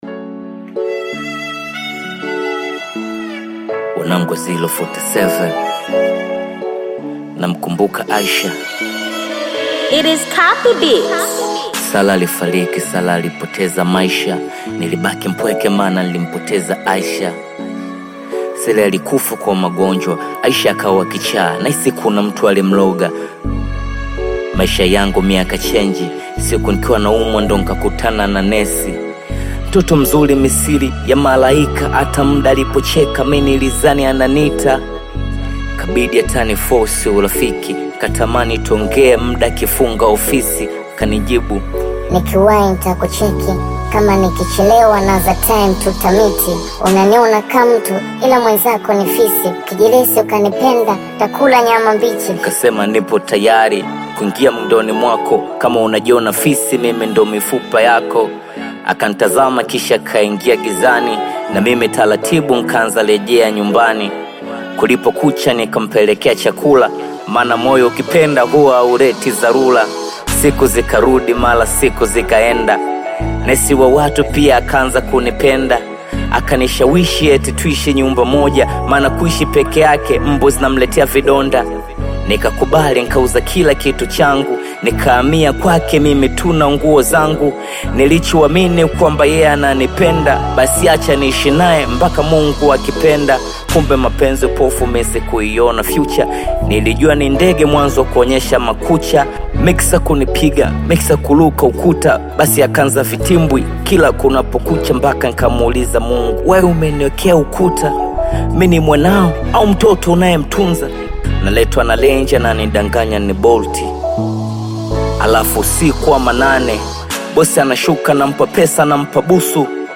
Bongo Flava music track
Tanzanian Bongo Flava artist, singer, and songwriter